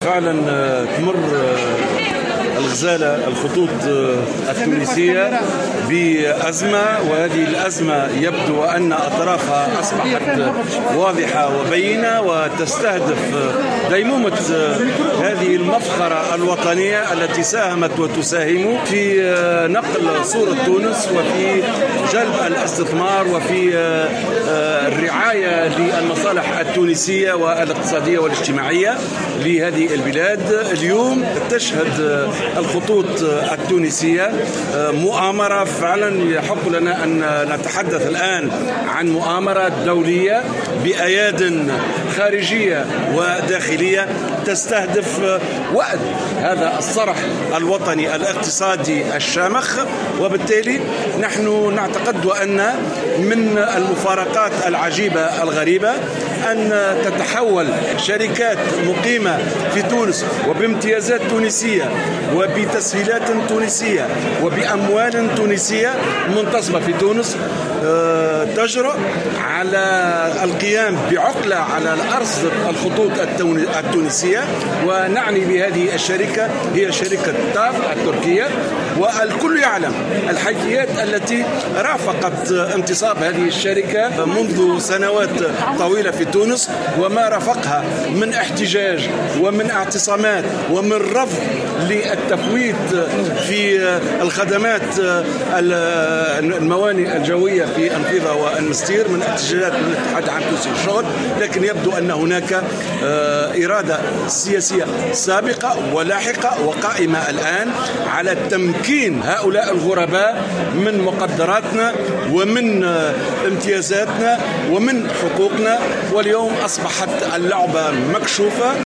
خلال إضراب أسلاك مجمع الخطوط الجوية التونسية